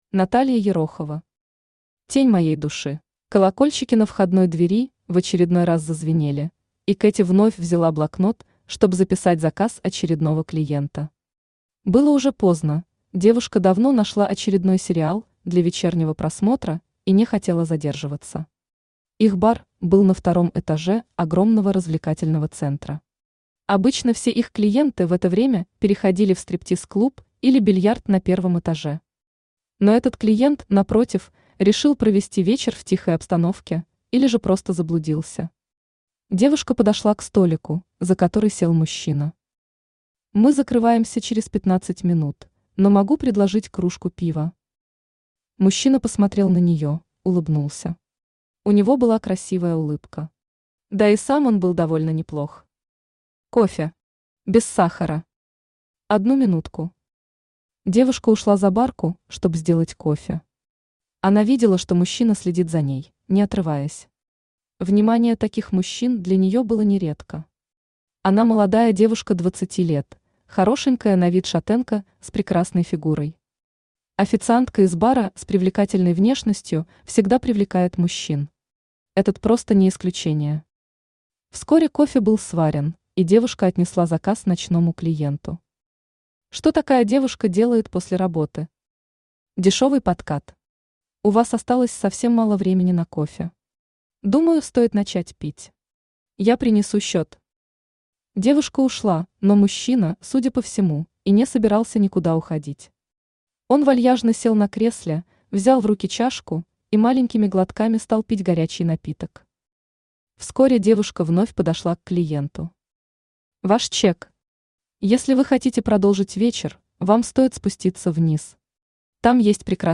Aудиокнига Тень моей души Автор Наталья Александровна Ерохова Читает аудиокнигу Авточтец ЛитРес.